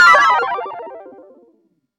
Game Over
A descending game over jingle with somber tones and a final low note
game-over.mp3